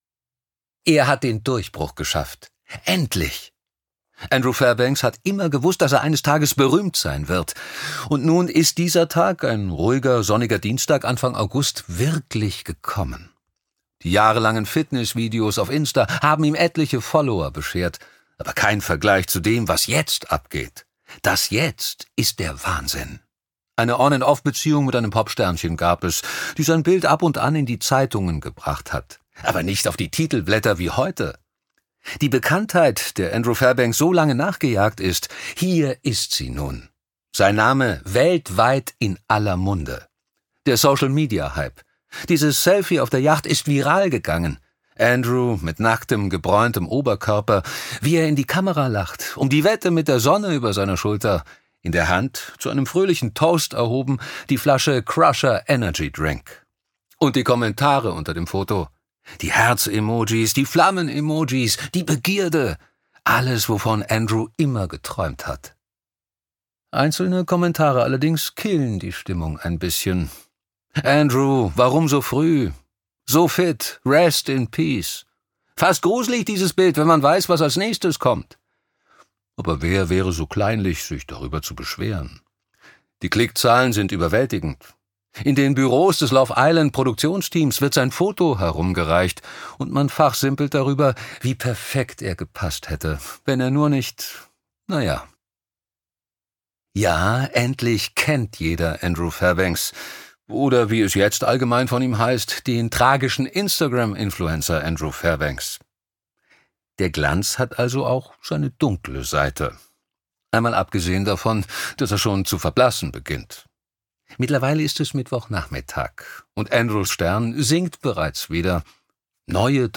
ungekürzte Lesung
Dazu die beiden Sprecher, die das Ganze zu einem klasse Hörerlebnis werden lassen.